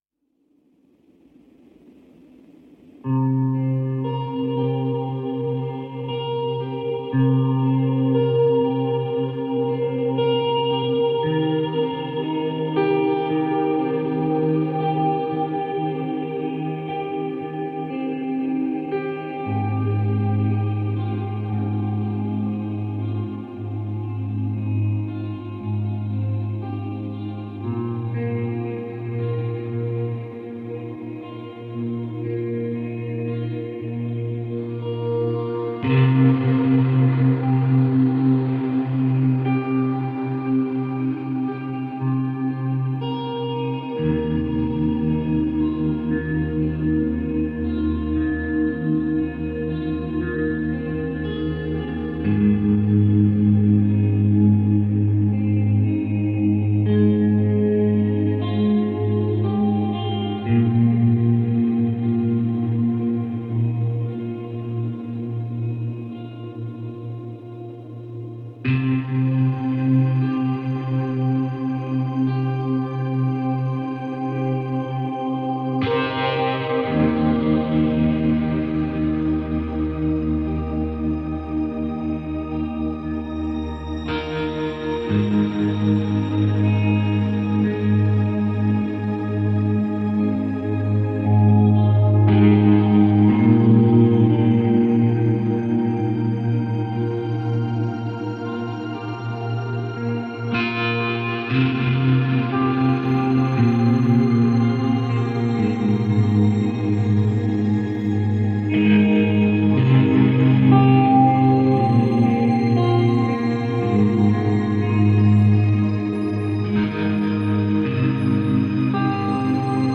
ژانر: چاکرا